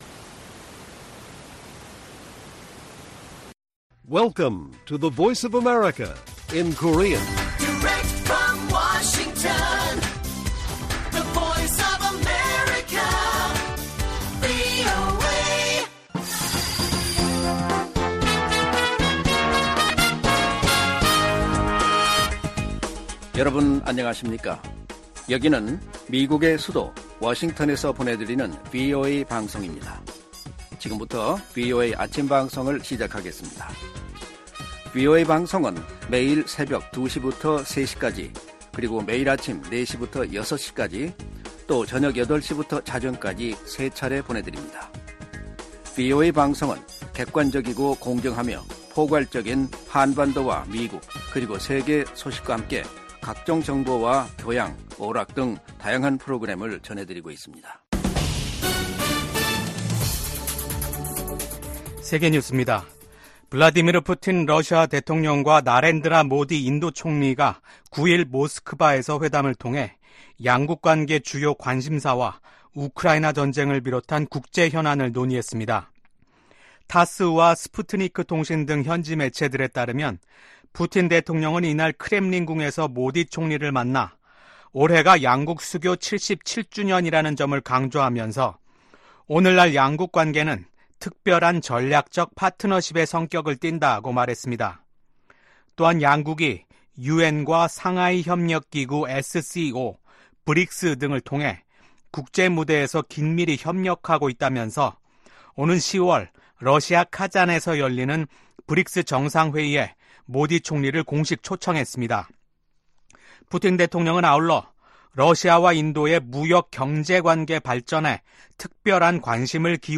세계 뉴스와 함께 미국의 모든 것을 소개하는 '생방송 여기는 워싱턴입니다', 2024년 7월 10일 아침 방송입니다. '지구촌 오늘'에서는 9일부터 미국 워싱턴 D.C.에서 열리는 북대서양조약기구(NATO) 정상회의 소식 전해드리고 '아메리카 나우'에서는 조 바이든 대통령의 대선 후보직 사퇴를 요구하는 목소리가 이어지고 있다는 소식 전해드립니다.